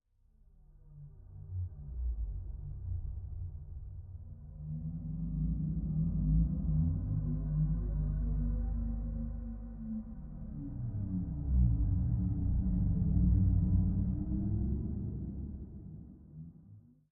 Minecraft Version Minecraft Version latest Latest Release | Latest Snapshot latest / assets / minecraft / sounds / ambient / underwater / additions / dark1.ogg Compare With Compare With Latest Release | Latest Snapshot